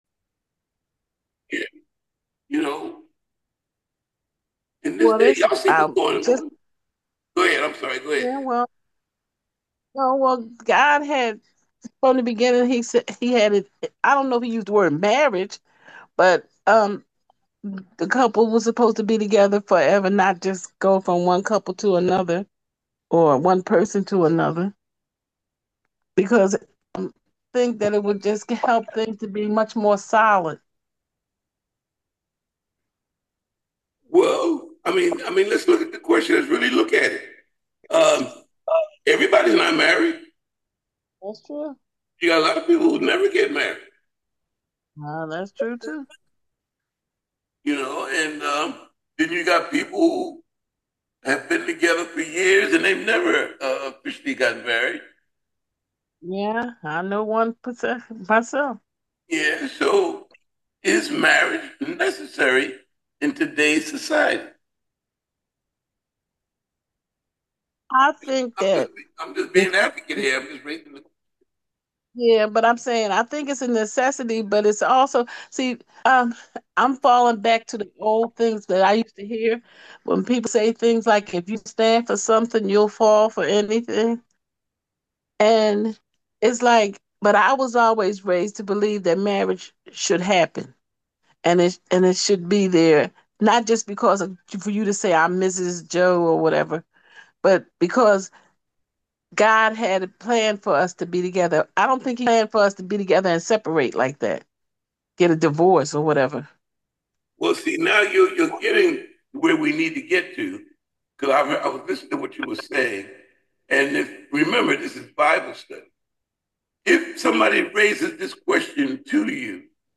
Is Marriage Necessary Today? Bible Study
Is-marriage-necessary-today_bible-study_audio_1.m4a